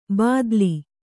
♪ bādli